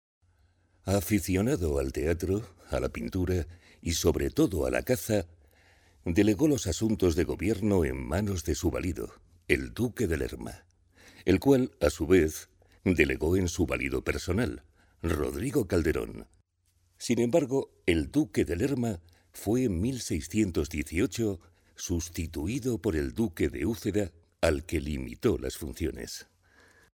Locutores españoles. Voces de locutores mayores